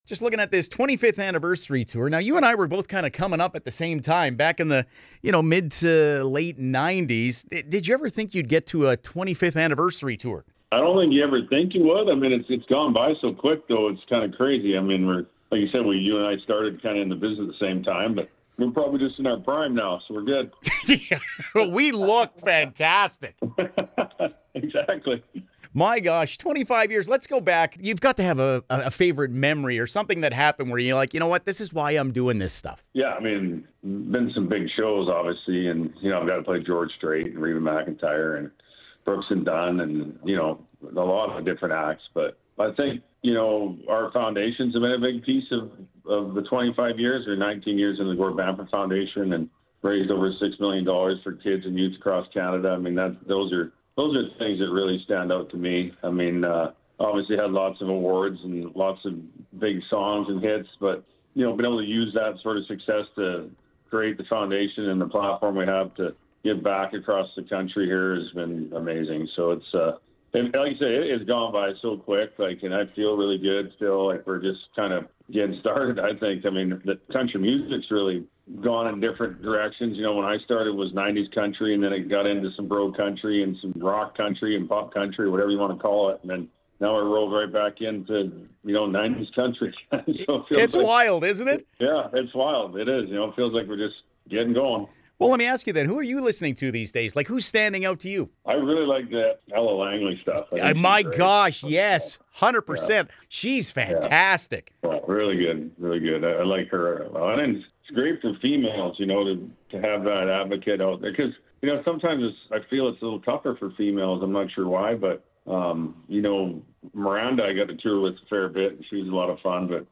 gord-bamford-chat.mp3